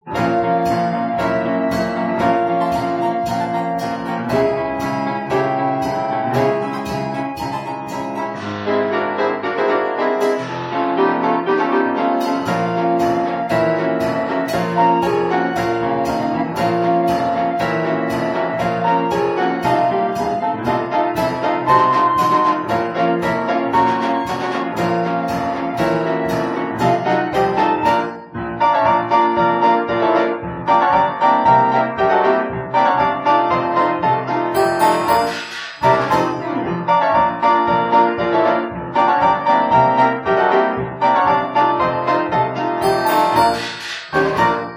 From Sept, 2000 MiniDisk Recording Session